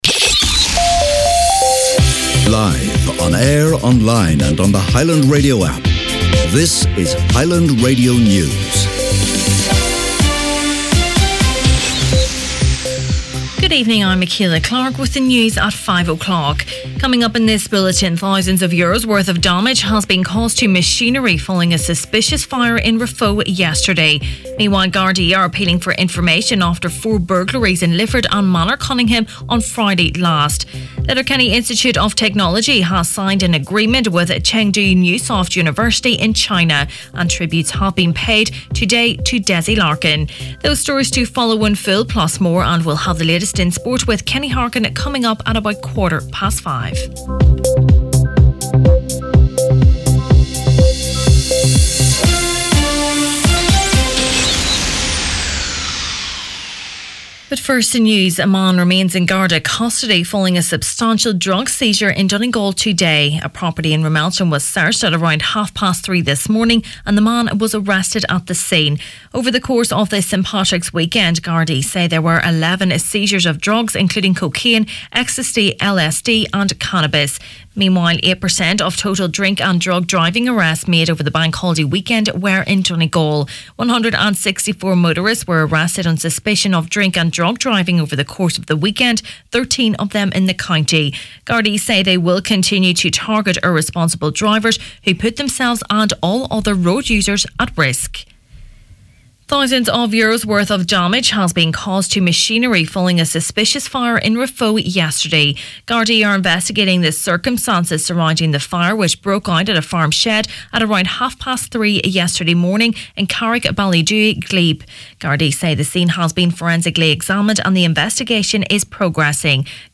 Main Evening News, Sport and Obituaries Tuesday March 19th